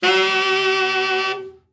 Index of /90_sSampleCDs/Giga Samples Collection/Sax/SAXOVERBLOWN
TENOR OB  13.wav